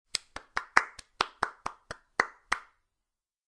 Download Clap sound effect for free.
Clap